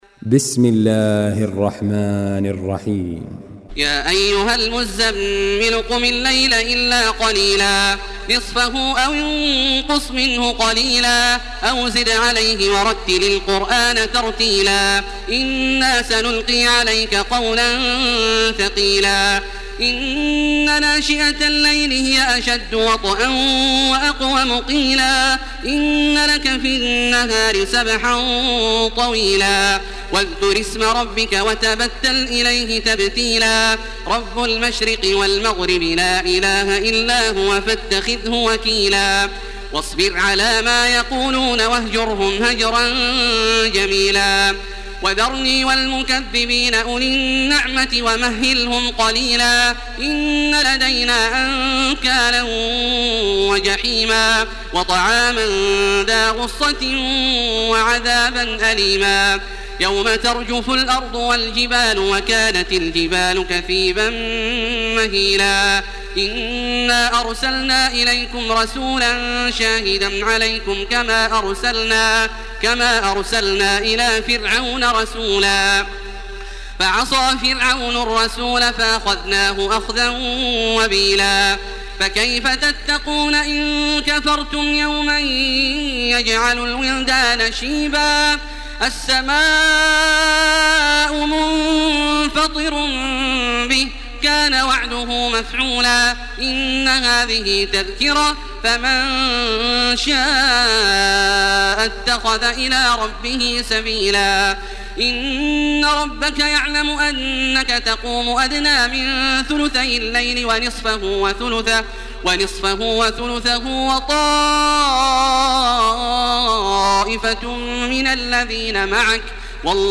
تحميل سورة المزمل بصوت تراويح الحرم المكي 1429
مرتل